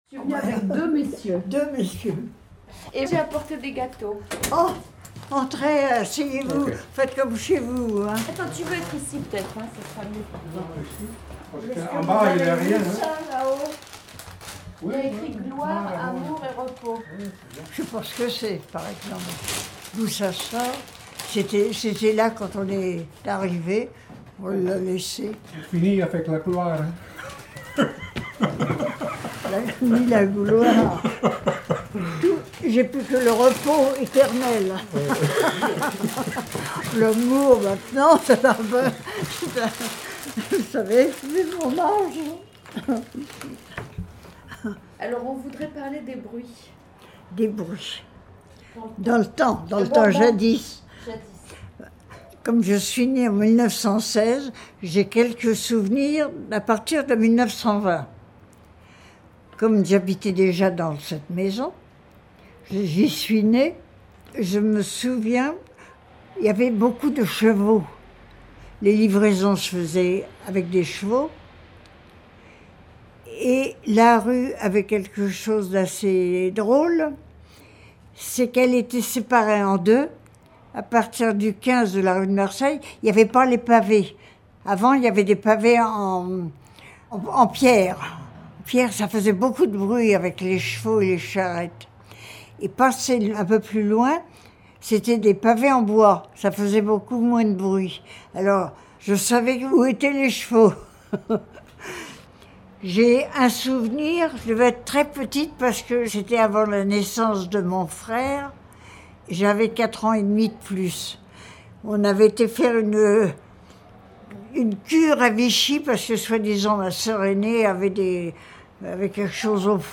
Pour introduire ce débat, un extrait audio du témoignage d'une ancienne habitante a été diffusé.